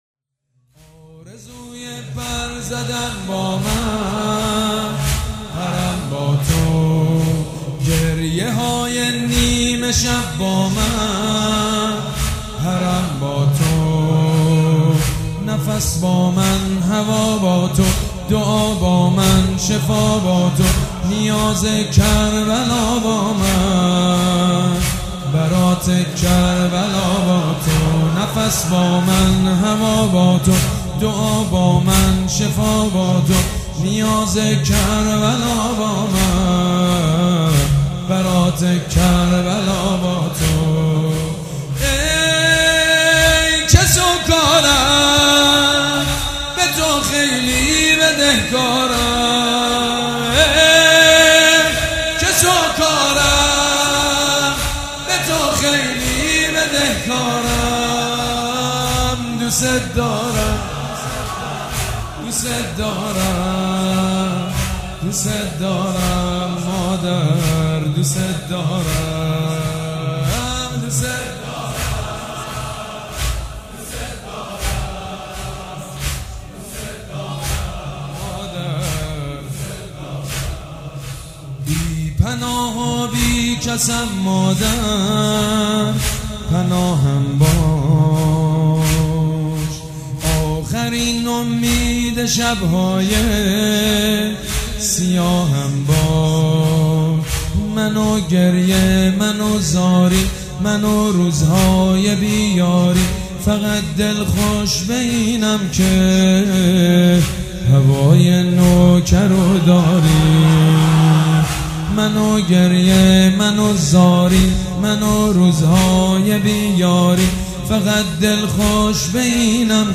«فاطمیه 1396» زمینه: آرزوی پر زدن با من، پرم با تو
«فاطمیه 1396» زمینه: آرزوی پر زدن با من، پرم با تو خطیب: سید مجید بنی فاطمه مدت زمان: 00:04:31